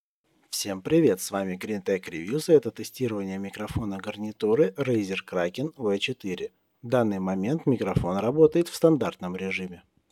Схема захвата кардиоидного микрофона Razer™ HyperClear обеспечивает отличную изоляцию голоса, поскольку чувствительна к вашему голосу, но при этом способна подавлять сторонние шумы сзади и по бокам.
mic_std.mp3